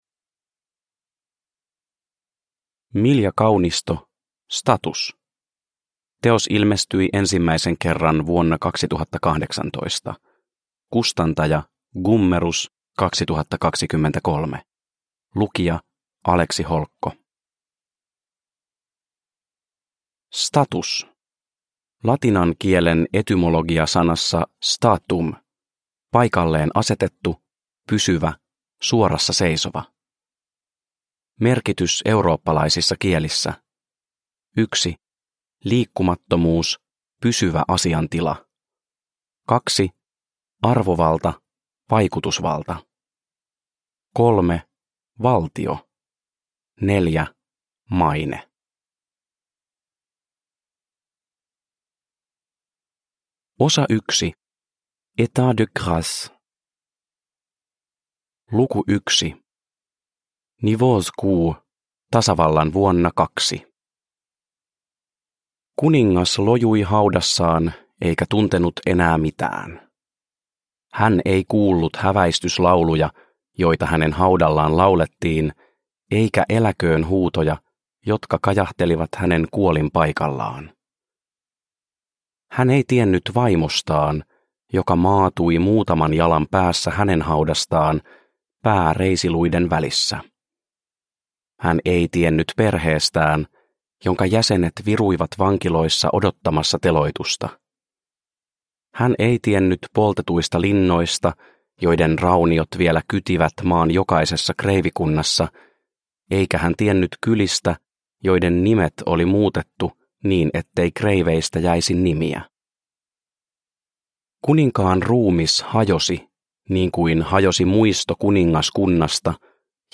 Status – Ljudbok – Laddas ner